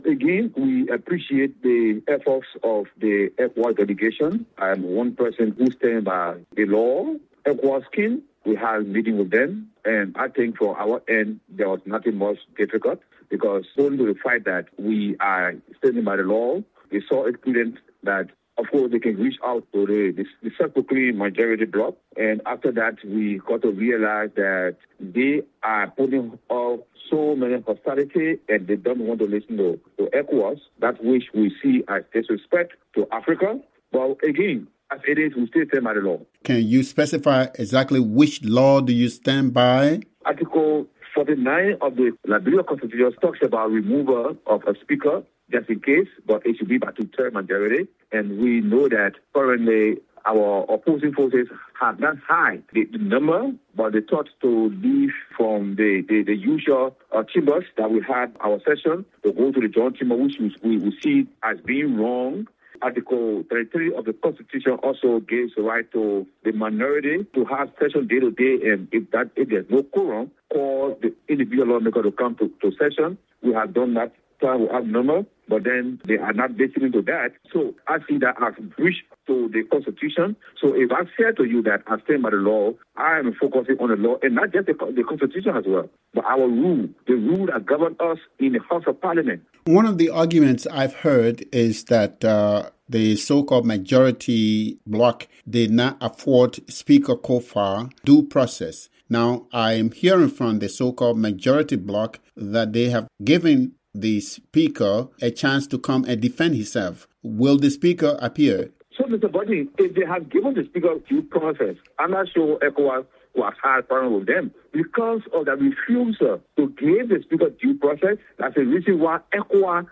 A member of the minority bloc in Liberia’s parliamentary impasse says he thinks the majority has shown disrespect to the Economic Community of West African States parliamentary mediation delegation. For weeks now, a group of lawmakers claiming to be the majority in the House of Representatives have been calling for Speaker J. Fonati Koffa’s removal for alleged conflict of interest.